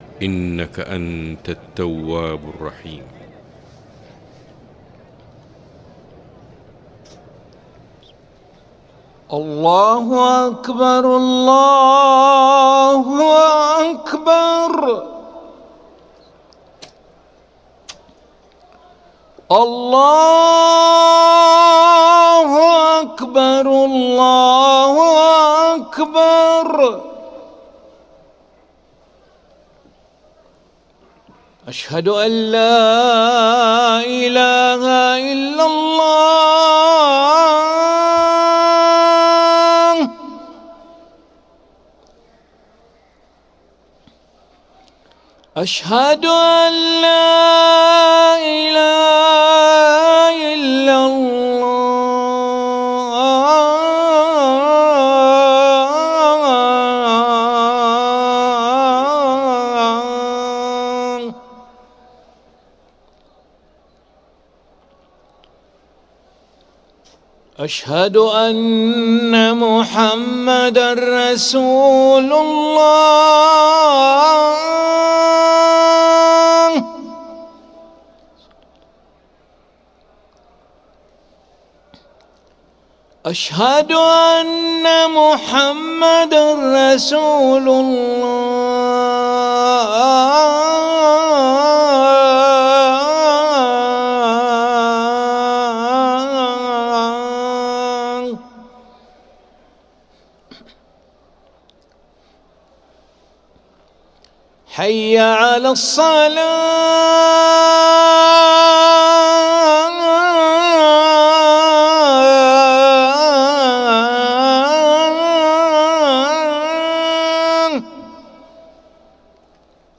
أذان العشاء للمؤذن علي ملا الأحد 7 ذو الحجة 1444هـ > ١٤٤٤ 🕋 > ركن الأذان 🕋 > المزيد - تلاوات الحرمين